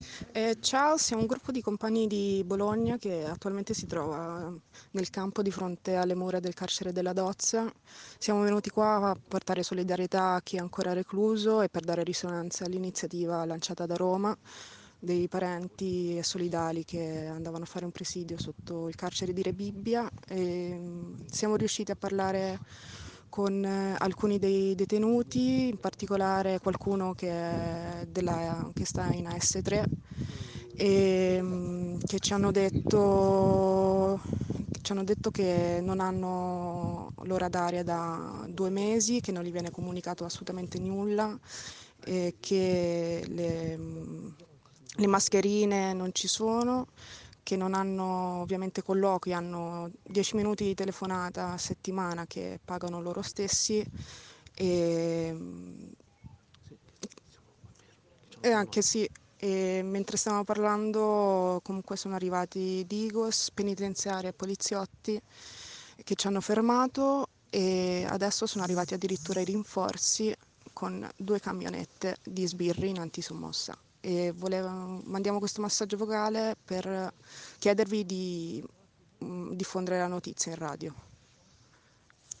Attached: 1 audio Riceviamo questa mattina e inoltriamo, da davanti al carcere della Dozza. Ci chiedono di fare girare questo messaggio vocale.